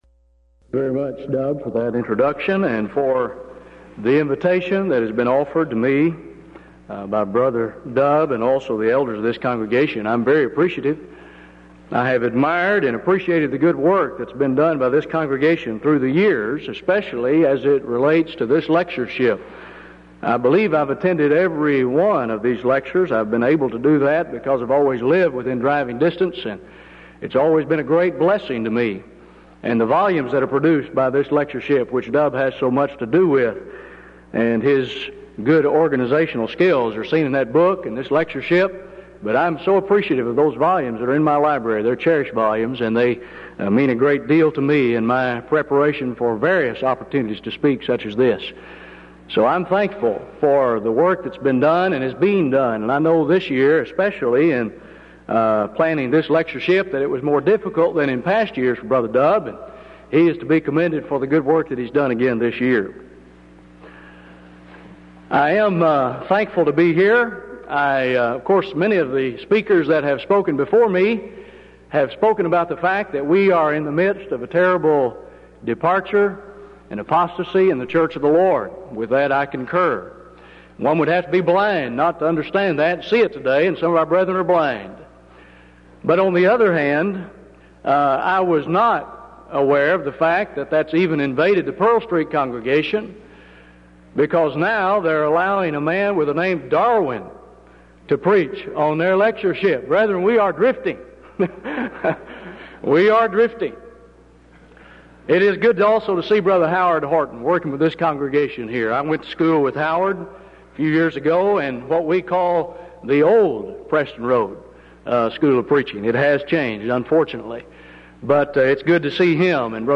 Event: 1992 Denton Lectures
lecture